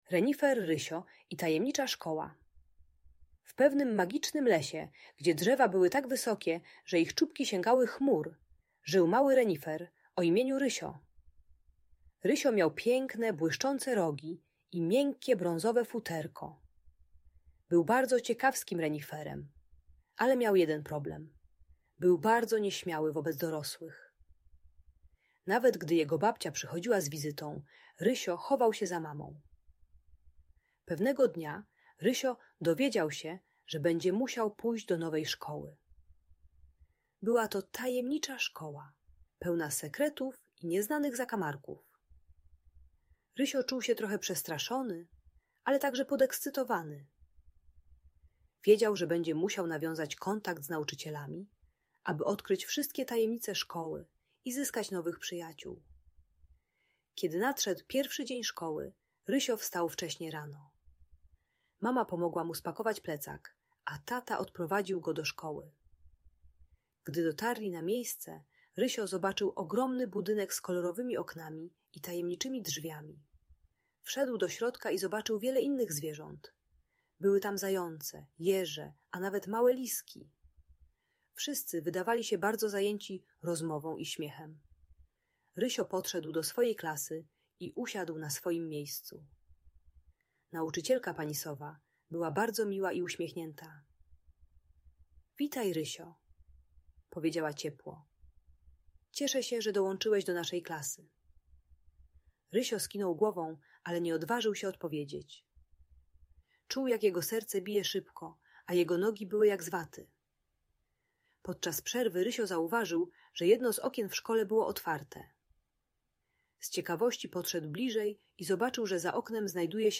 Renifer Rysio i Tajemnicza Szkoła - Audiobajka dla dzieci